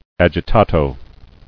[ag·i·ta·to]